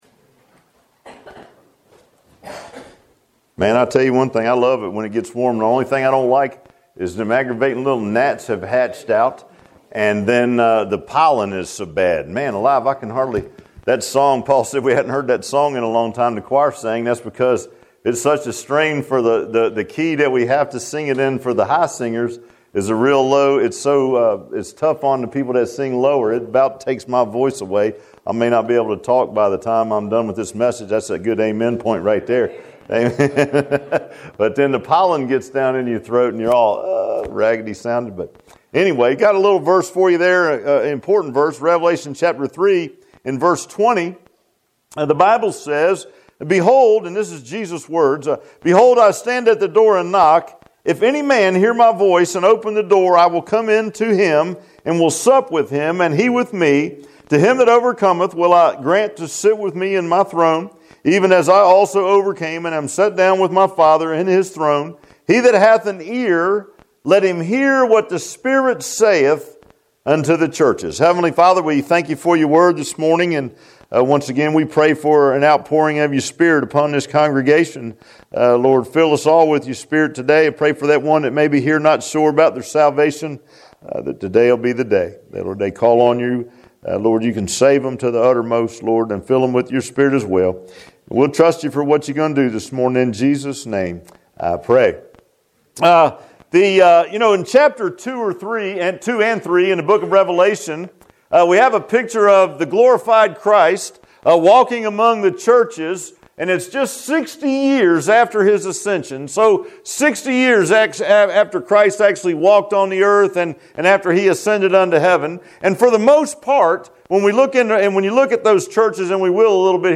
Passage: Revelation 3:20-21 Service Type: Sunday AM